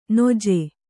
♪ noje